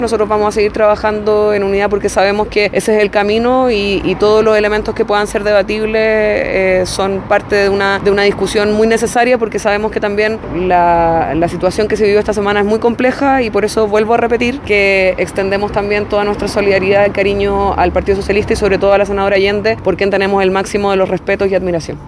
De todas formas, en entrevista con Estado Nacional, la líder del PS indicó que se podría haber previsto otro escenario, planteando como opción que Allende pudo haber salido del cargo, para evitar el juicio del TC, por otro nombramiento: “Si se le nombra embajadora o ministra”.